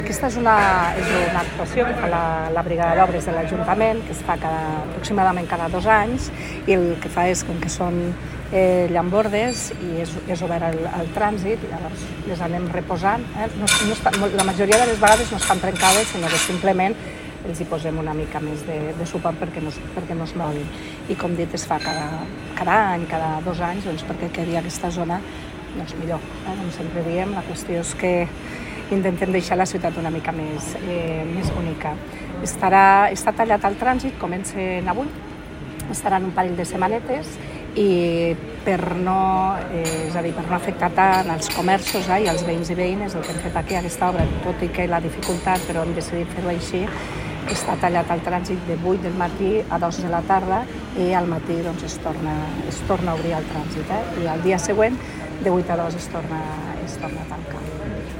Tall de veu de l'alcaldessa accidental, Begoña Iglesias, sobre l'actuació al carrer Democràcia (869.3 KB) Tall de veu de l'alcaldessa accidental, Begoña Iglesias, sobre les obres al carrer Victòria Kent (574.3 KB)